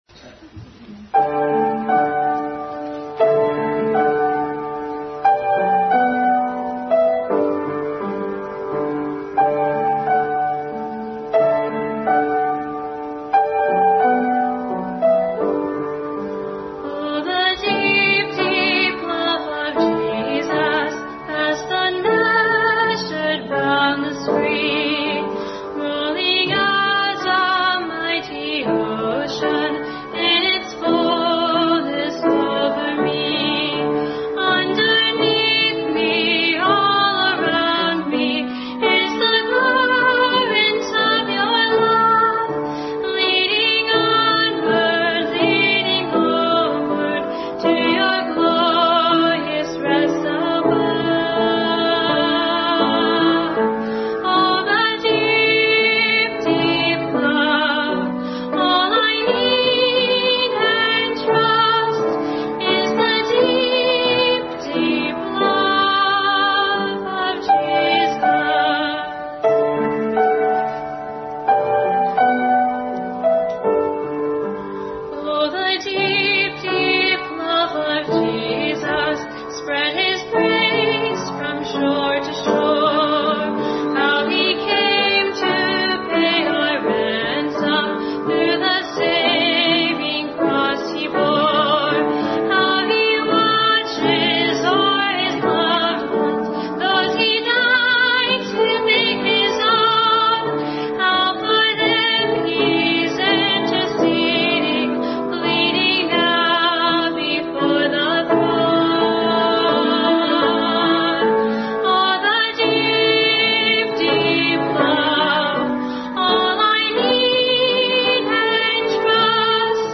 Service Type: Family Bible Hour Bible Text: Revalation 3:21-22 and others.